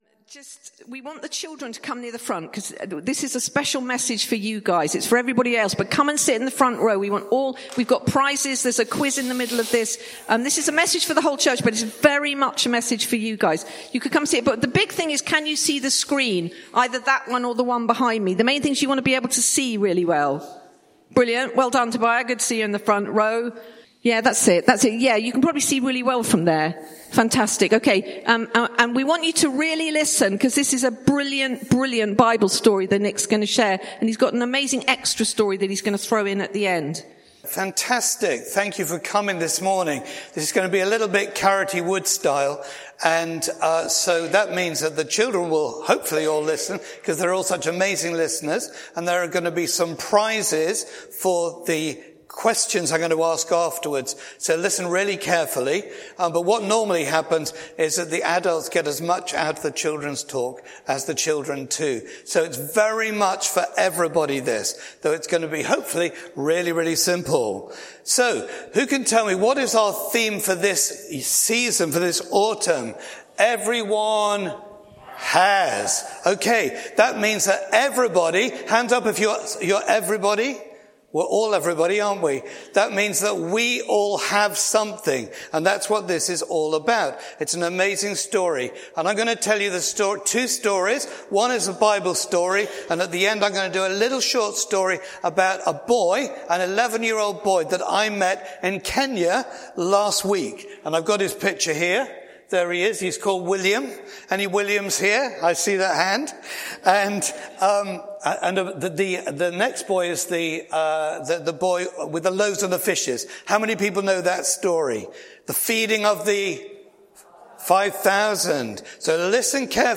Bassett Street Sermons